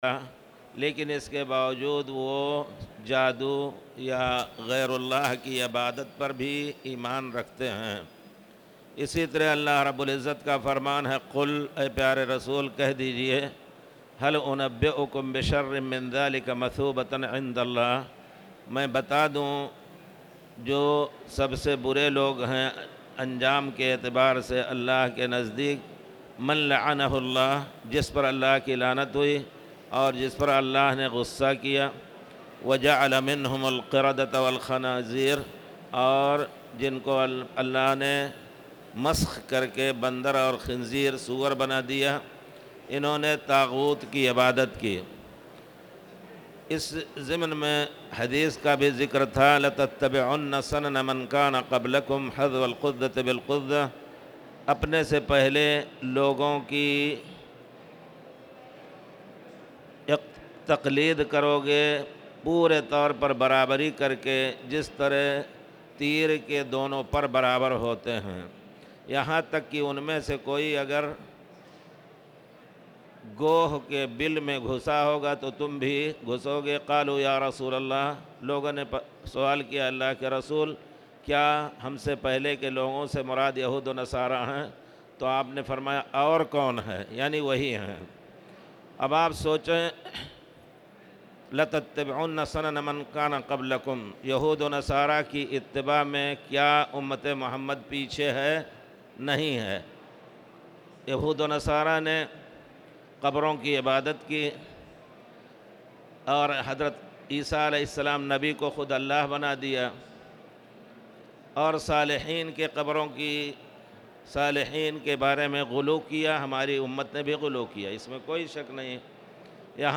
تاريخ النشر ٣ ذو الحجة ١٤٣٨ هـ المكان: المسجد الحرام الشيخ